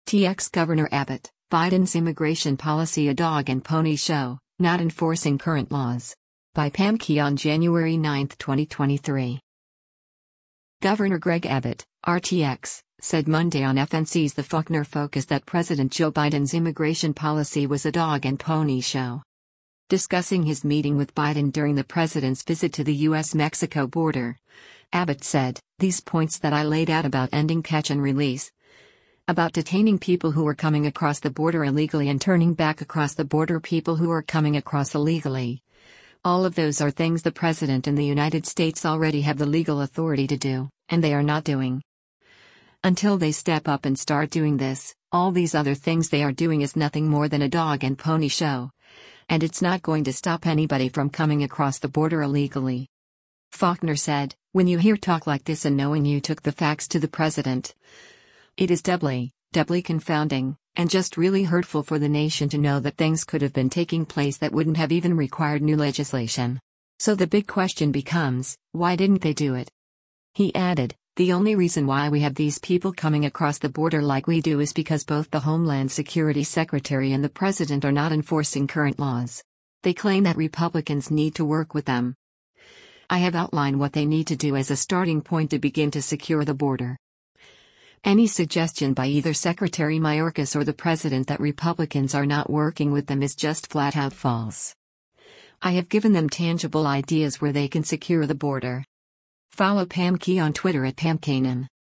Governor Greg Abbott (R-TX) said Monday on FNC’s “The Faulkner Focus” that President Joe Biden’s immigration policy was a “dog and pony show.”